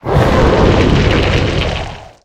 Cri de Monthracite dans Pokémon HOME.